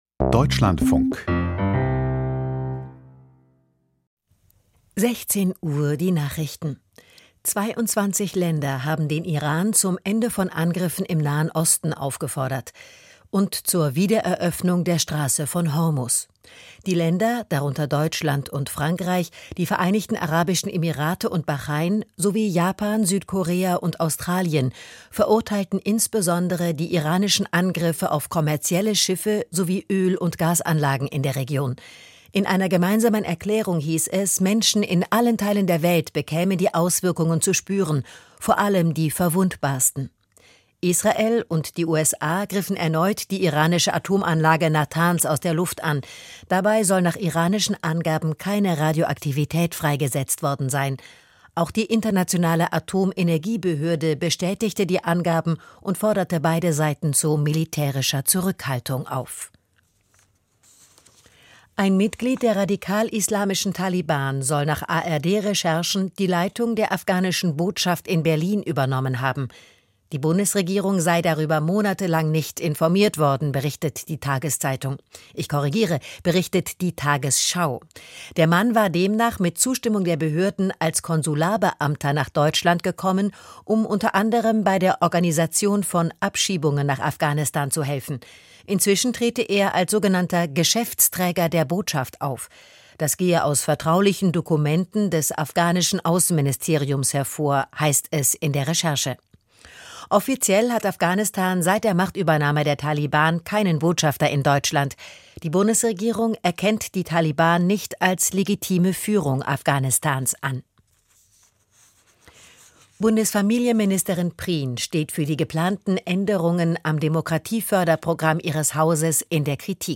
Aus der Deutschlandfunk-Nachrichtenredaktion.